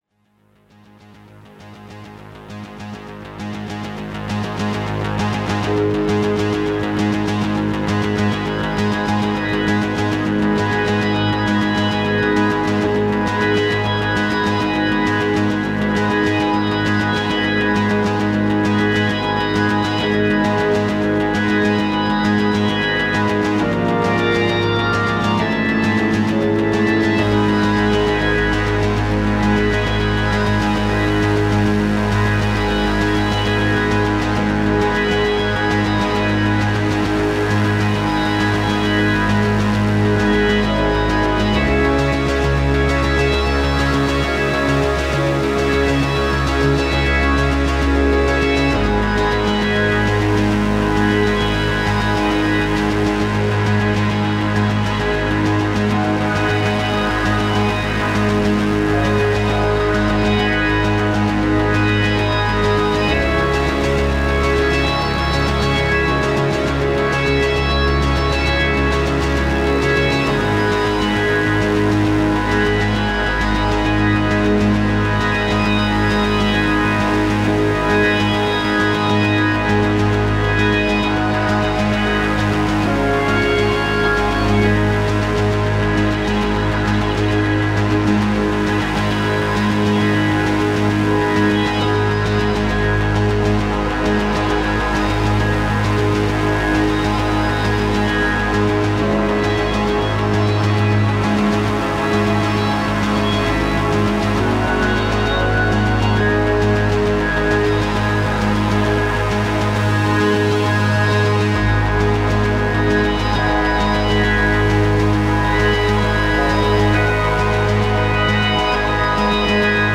instrumental
Recorded AAD - Analogue Analogue Digital